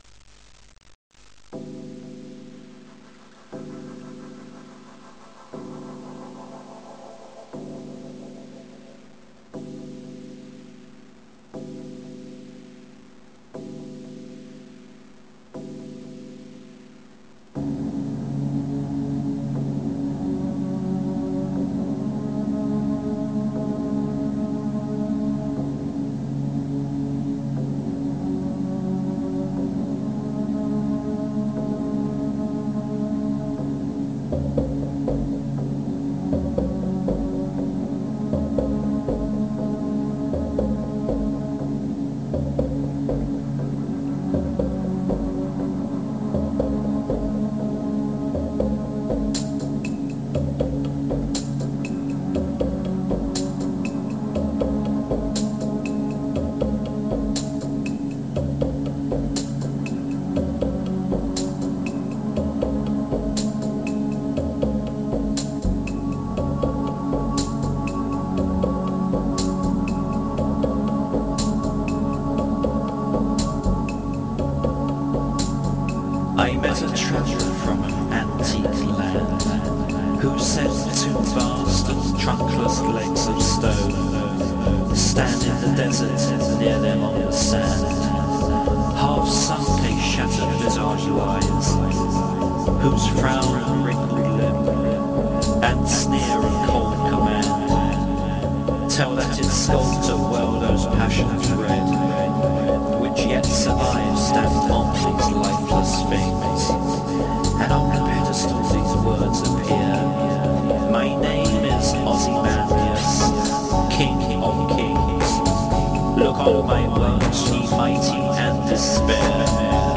Recited by and with original music